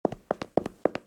walk.ogg